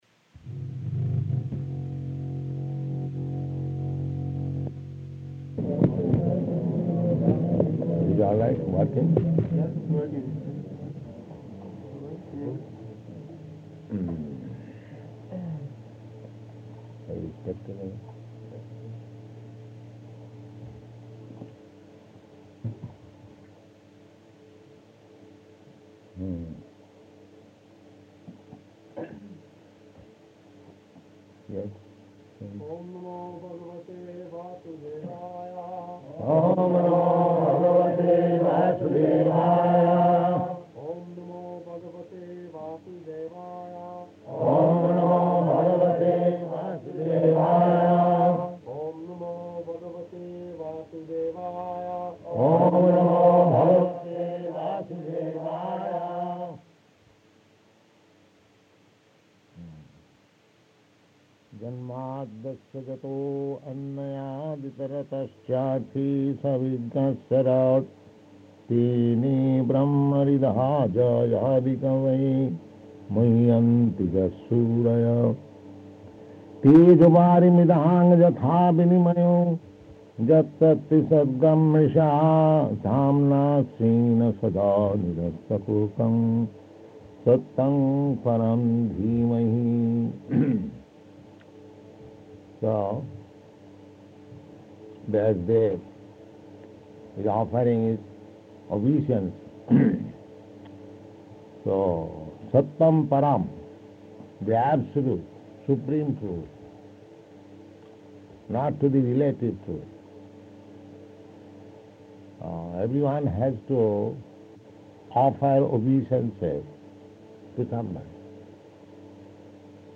Śrīmad-Bhāgavatam 1.1.1 --:-- --:-- Type: Srimad-Bhagavatam Dated: August 6th 1971 Location: London Audio file: 710806SB-LONDON.mp3 [poor audio] Prabhupāda: It is all right?